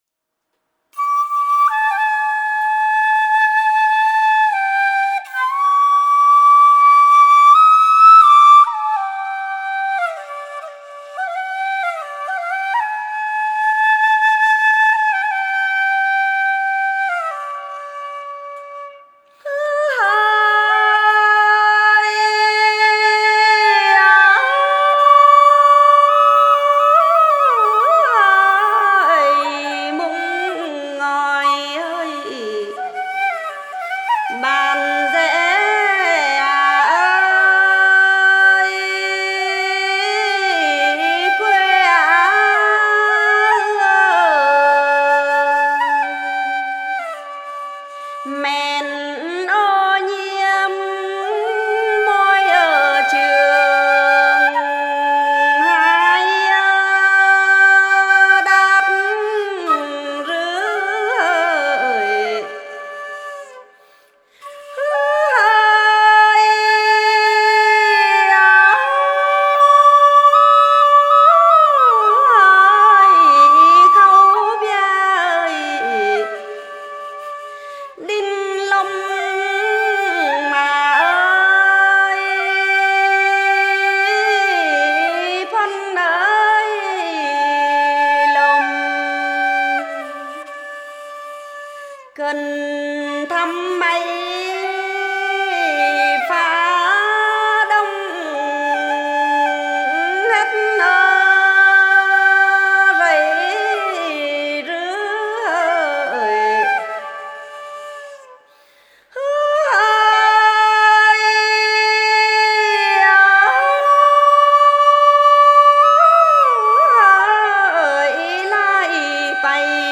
Thể loại: Lượn cọi và hát then đàn tính dân ca dân tộc Tày Cao Bằng